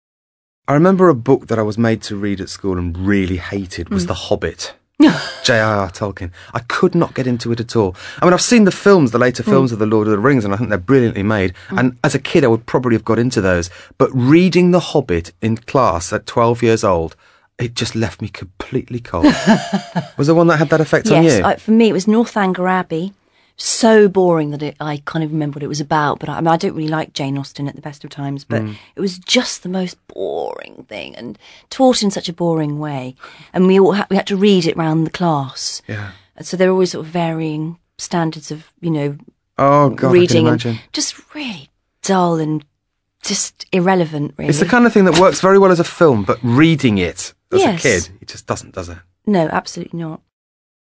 ACTIVITY 92: You will listen to a man and a woman talking about books they had to read at school.